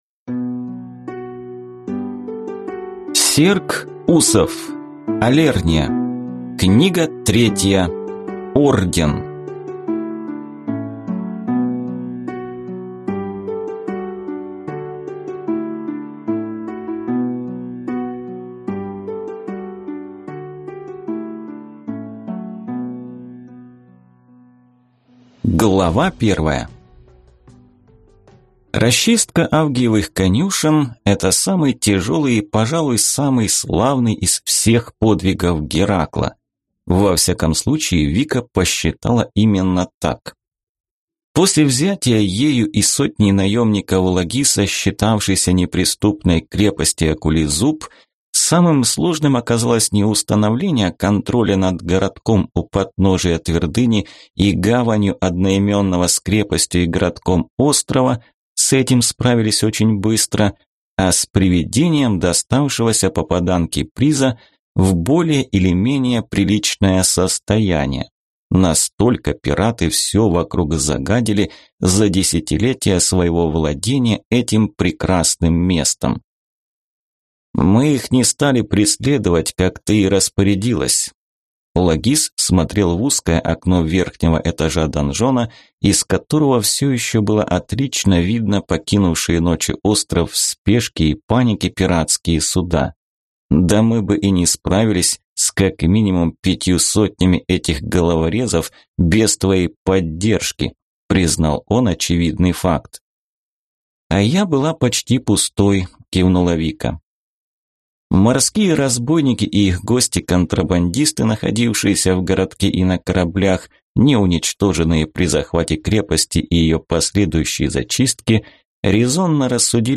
Аудиокнига Алерния. Орден | Библиотека аудиокниг